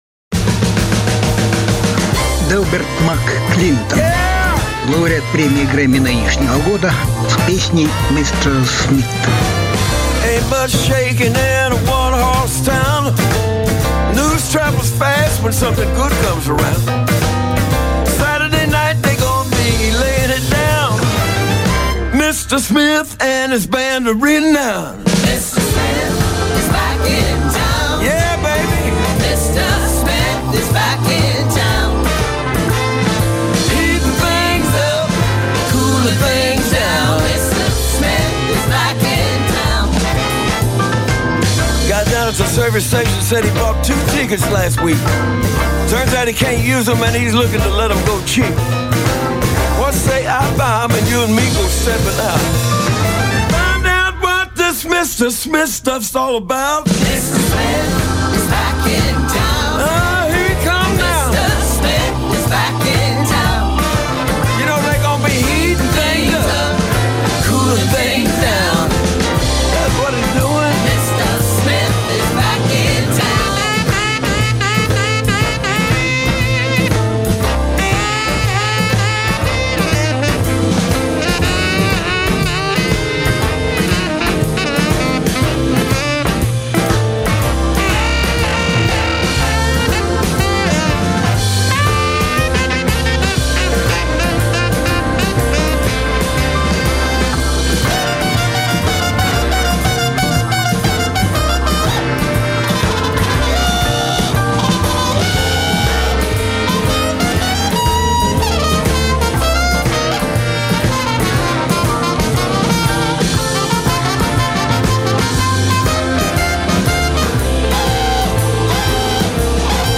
Различные альбомы Жанр: Блюз СОДЕРЖАНИЕ 11.05.2020 В январе прошла 62-я церемония вручения наград Grammy.